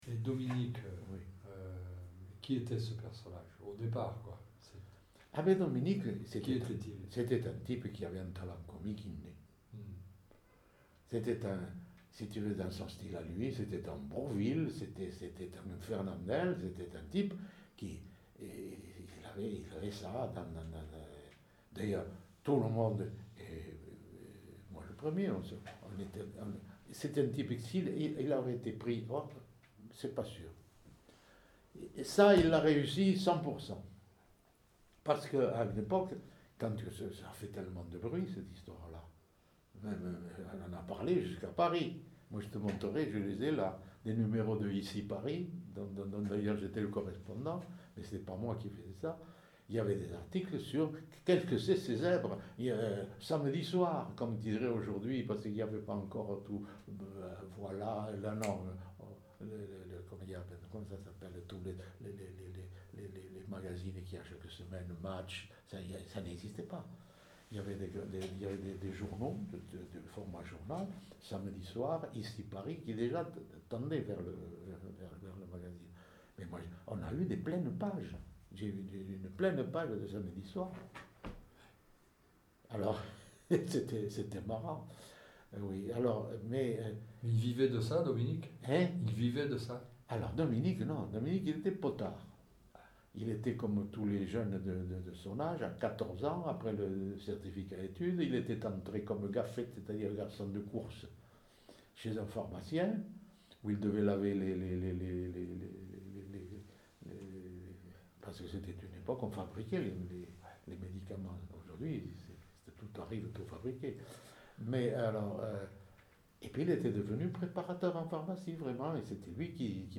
Aire culturelle : Pays toulousain ; Rouergue
Lieu : Saint-Sauveur
Genre : témoignage thématique